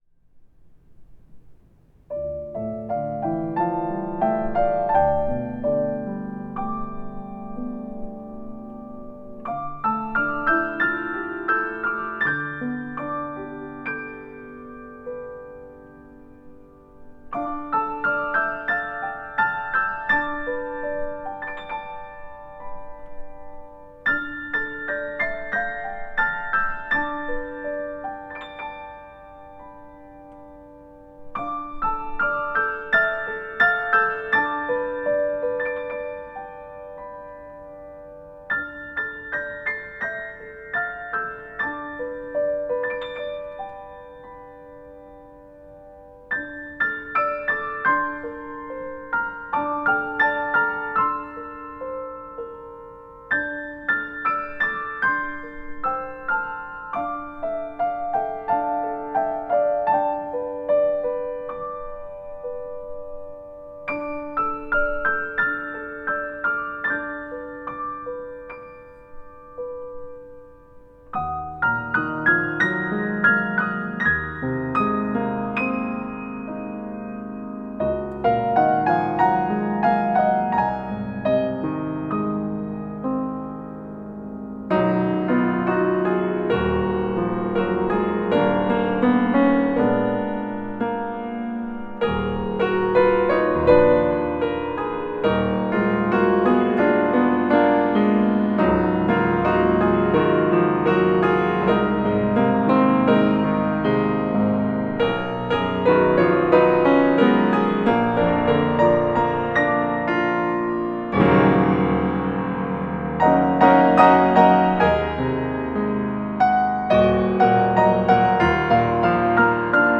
An ethereal piano solo setting of the Advent hymn